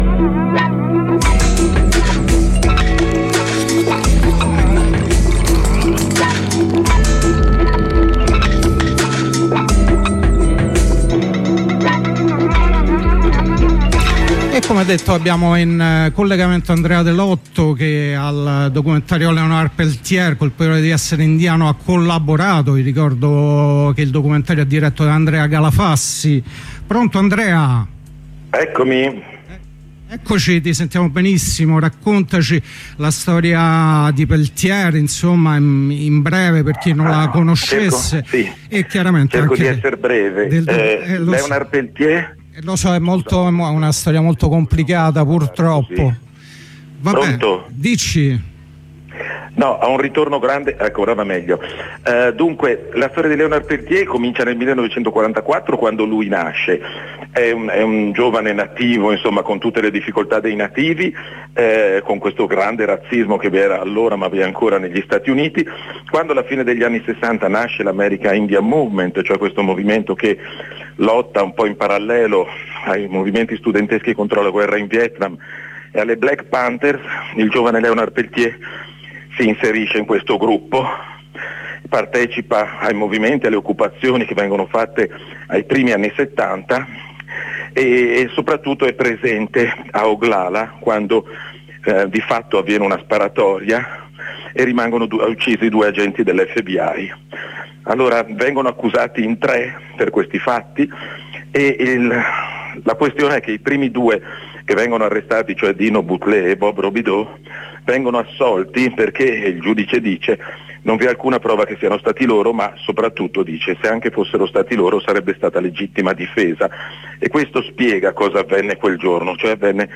Semilla N108 Techno House